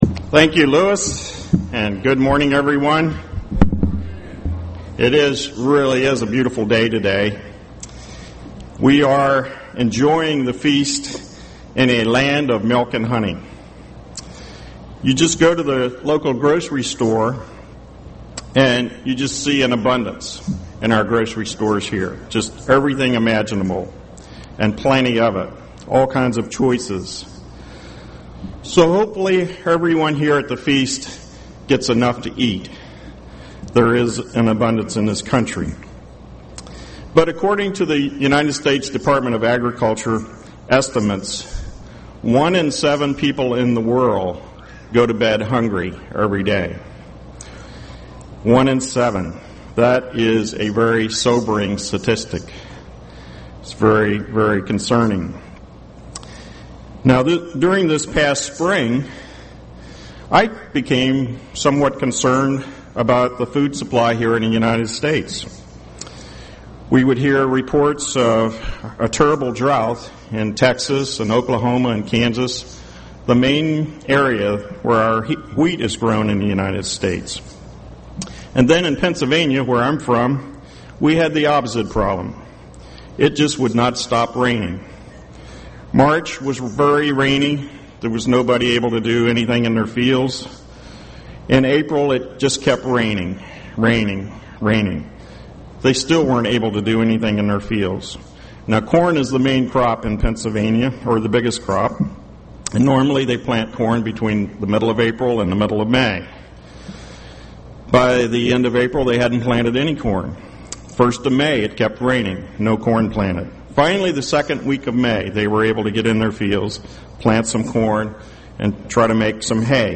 This sermon was given at the Jekyll Island, Georgia 2011 Feast site.